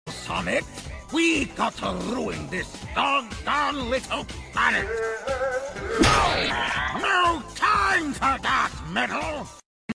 Metal Sonic🦔🤖 Hue Hue Hue sound effects free download